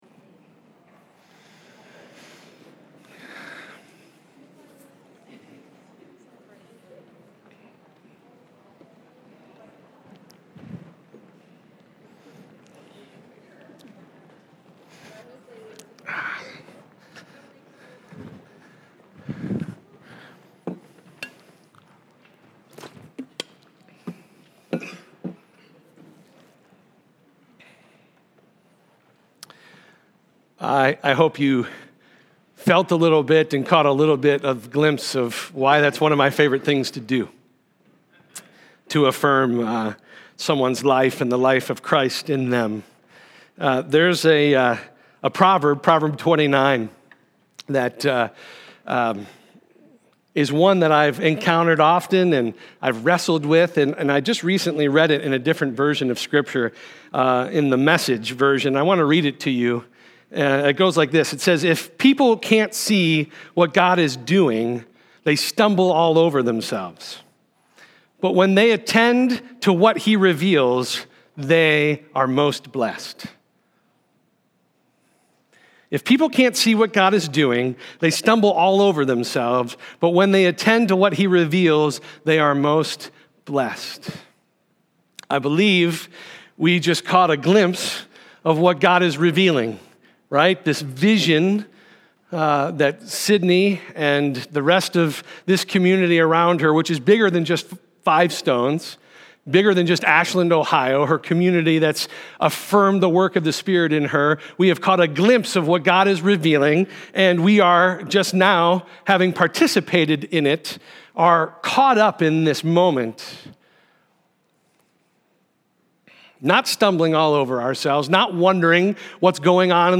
Sermons
1-5-20_5_stones_sermon.mp3